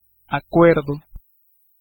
Ääntäminen
US : IPA : [ə.ˈreɪndʒ.mənt]